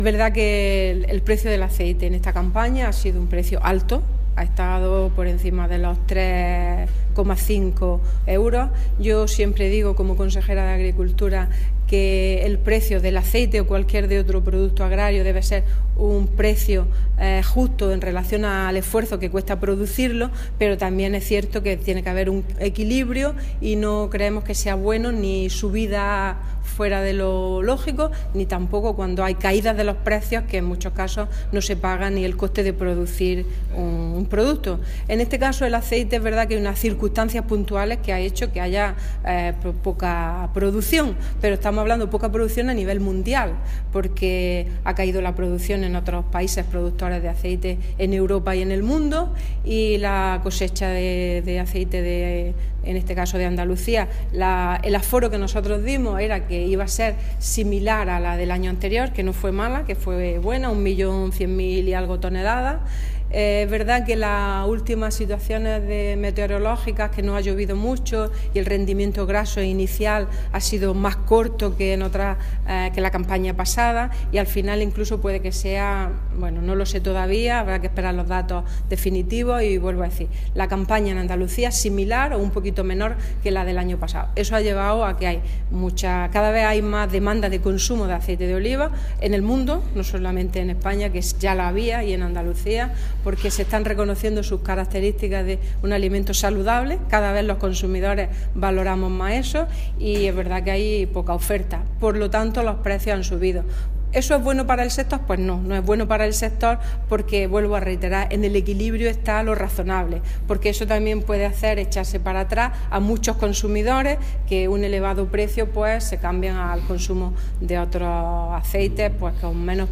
Declaraciones consejera precios aceite